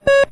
combat alert